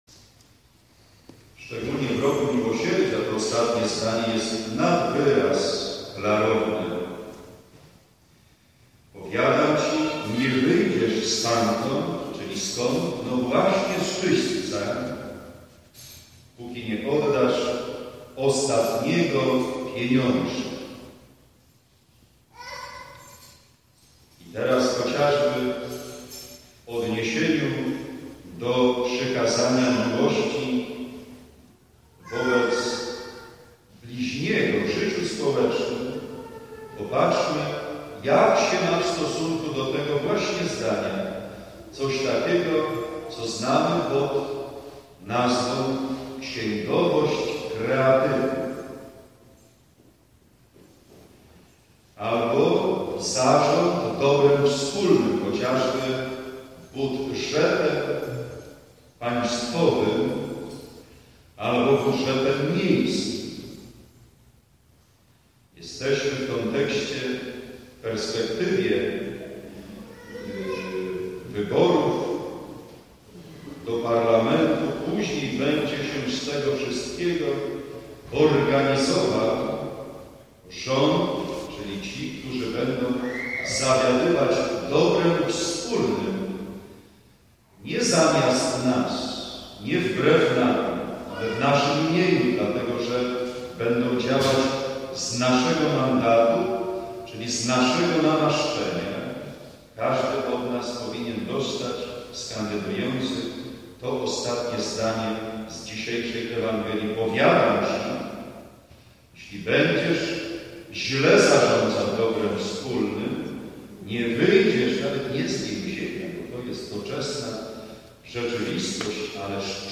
EWANGELIA Łk 12, 54-59 Poznać znaki czasu ____________________________ 23 października 2015 – Msza św. – homilia